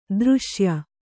♪ dřśya